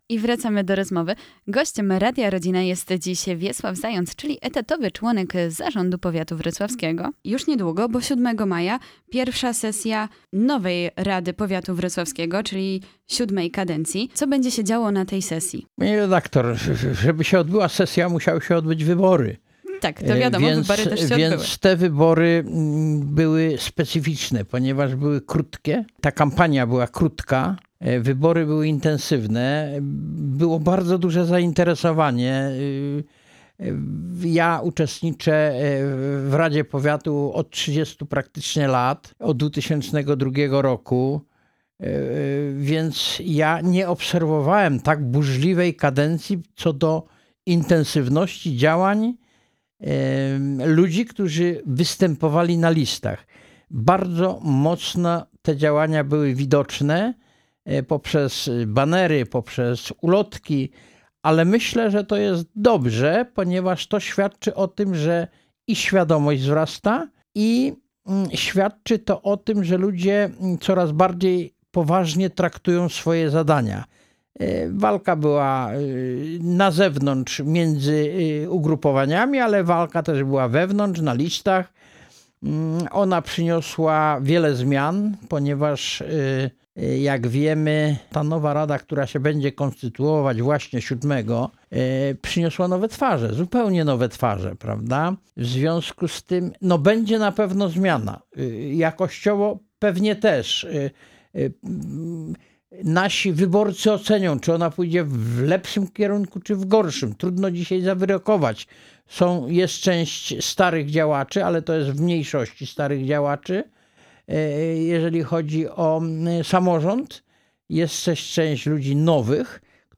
W studiu Radia Rodzina gościliśmy dziś Wiesława Zająca, który podsumował VI kadencję Rady Powiatu Wrocławskiego, opowiedział o perspektywach na kolejną, VII kadencję.